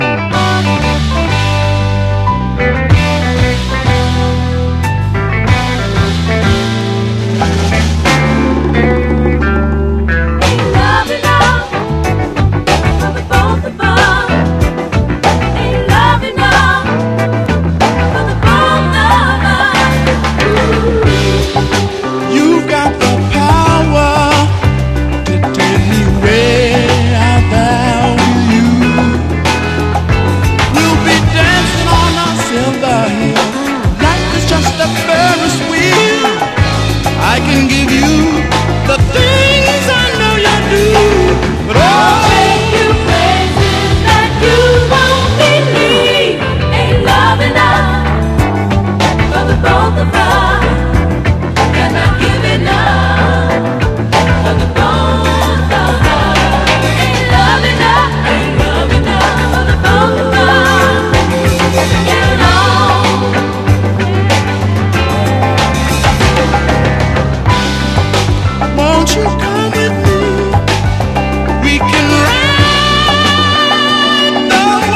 SOUL / SOUL / FREE SOUL / SOFT ROCK / S.S.W.